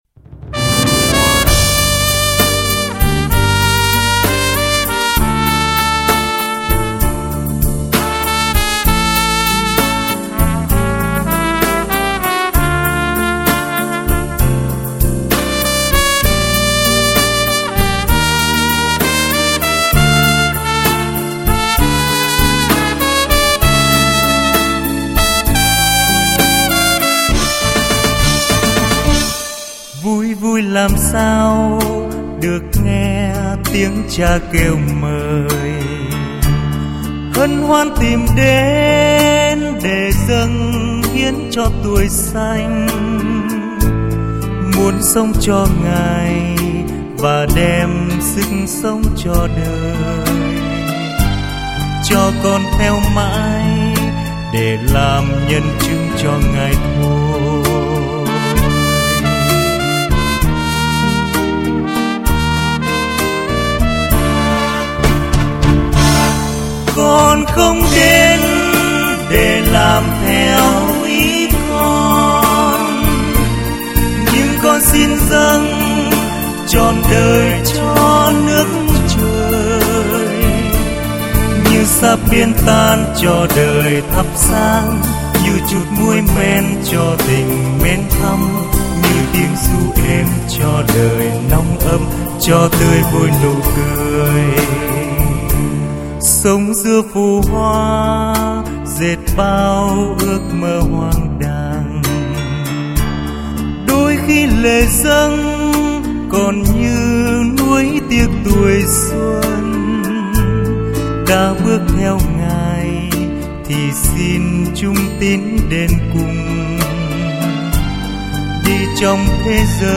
Nghe nhạc thánh ca. Bài hát được phát từ Website